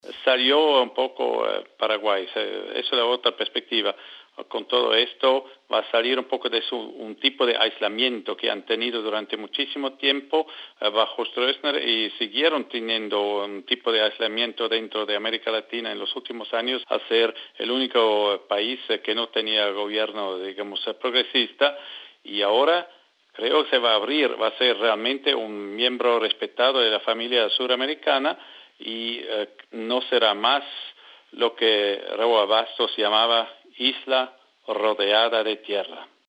Walter Suter, ex embajador de Suiza ante Paraguay, en entrevista con swissinfo.